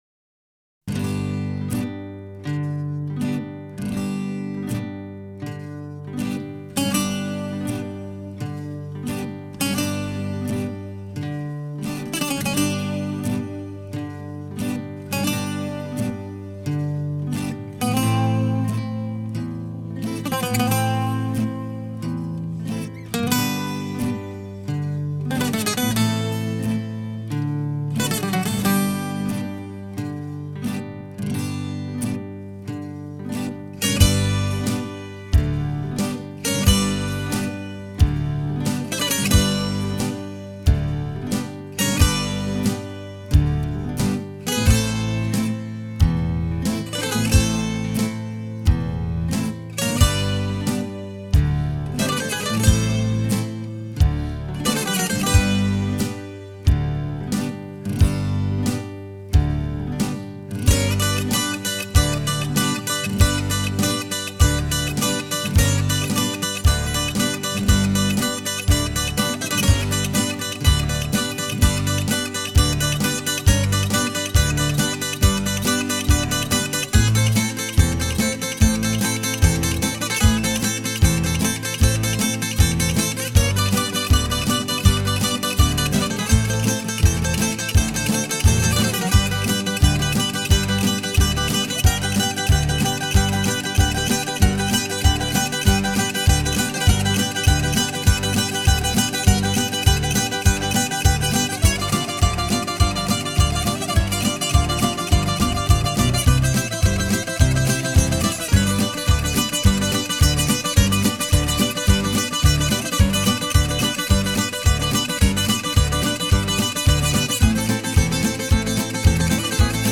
Greek Music, Sirtaki, Rebetiko